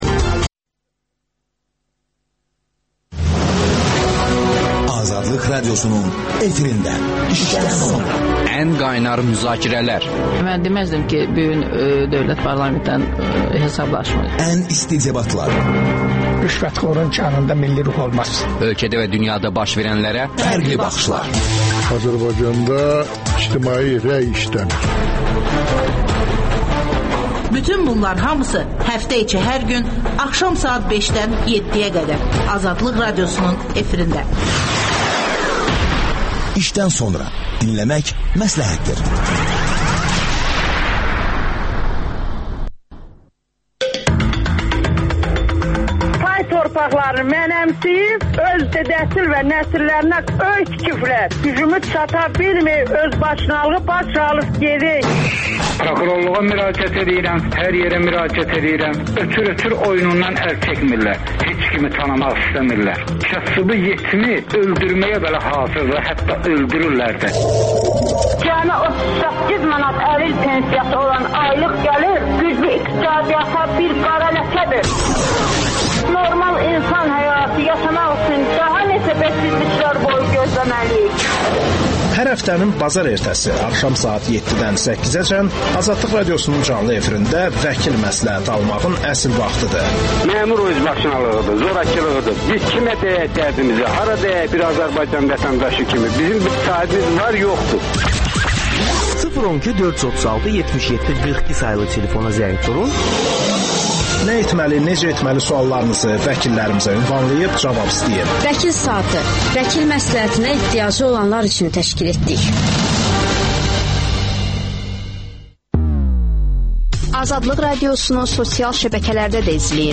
Rusiya bu təzyiqlərlə SSRİ-ni yenidən qura biləcəkmi? Siyasi şərhçilər